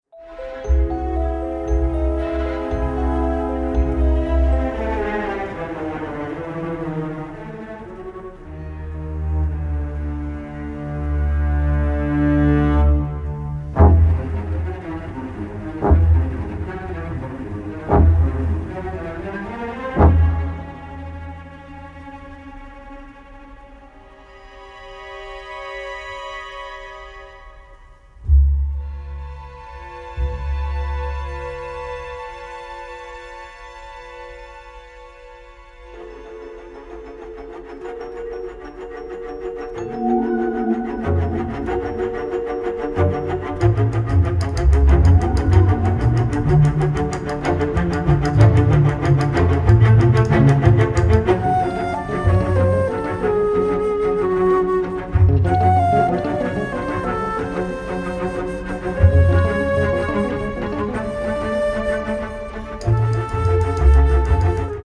original score
elegant and contemporary lounge-style melodies